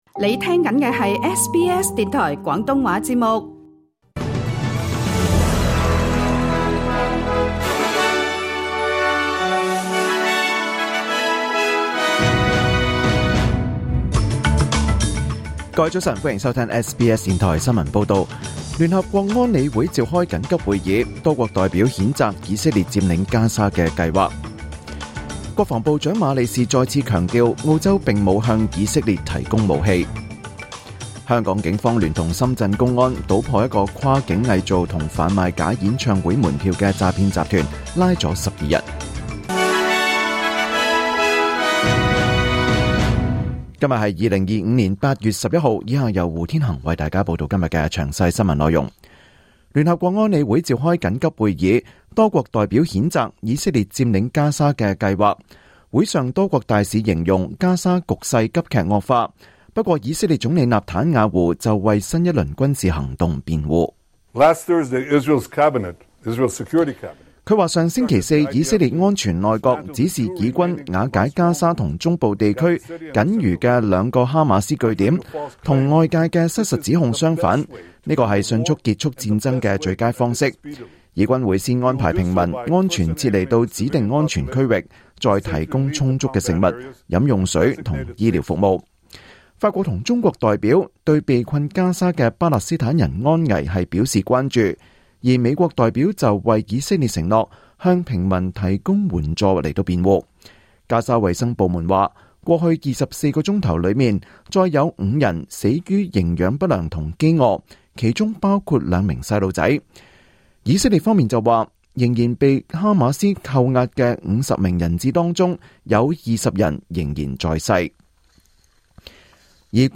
2025年8月11日SBS廣東話節目九點半新聞報道。